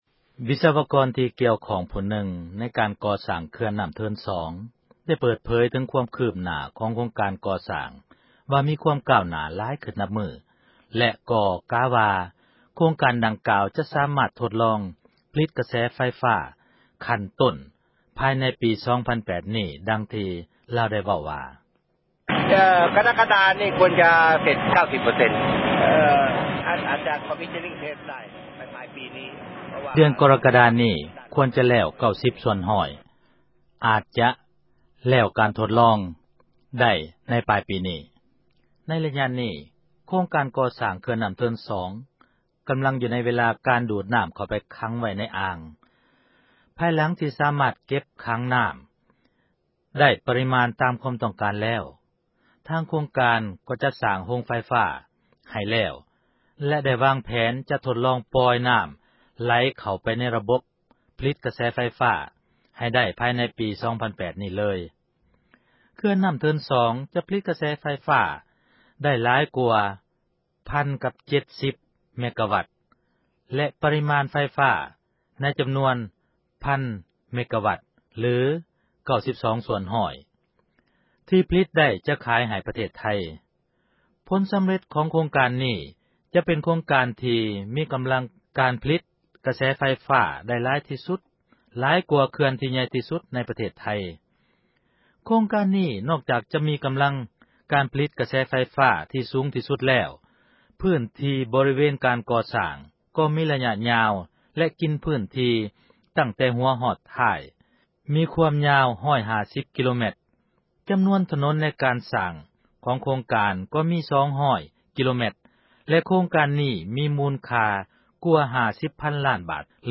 ວິສະວະກອນ ທີ່ກ່ຽວຂ້ອງ ຜູ້ນຶ່ງ ໃນການກໍ່ສ້າງ ເຂື່ອນນ້ຳເທີນ 2 ໄດ້ເປີດເຜີຍ ເຖິງຄວາມ ຄືບໜ້າ ຂອງໂຄງການ ກໍ່ສ້າງ ວ່າ ມີຄວາມ ກ້າວໜ້າ ຫລາຍຂື້ນ ນັບມື້ ແລະ ກໍກະວ່າ ໂຄງການ ດັ່ງກ່າວຈະສາມາດ ທົດລອງ ຜລິດ ກະແສ ໄຟຟ້າ ຂັ້ນຕົ້ນ ພາຍໃນປີ 2008 ນີ້, ດັ່ງທີ່ ລາວ ໄດ້ເວົ້າວ່າ: